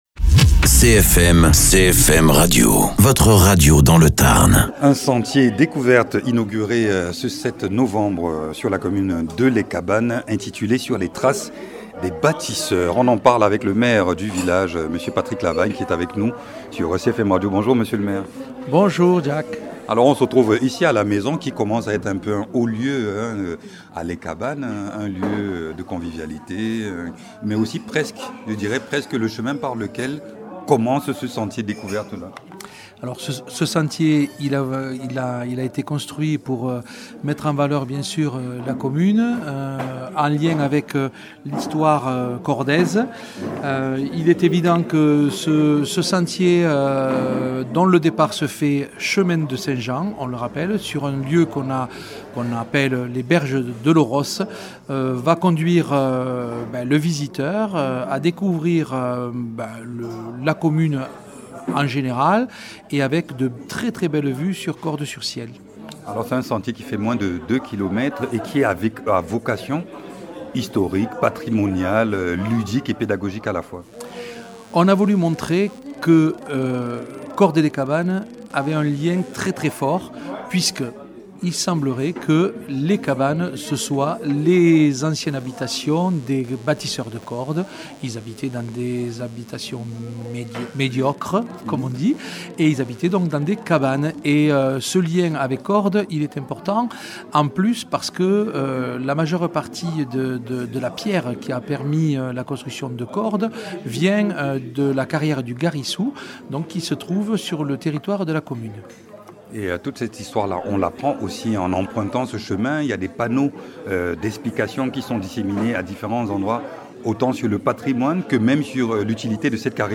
Interviews
Invité(s) : Patrick Lavagne, maire de Les Cabannes.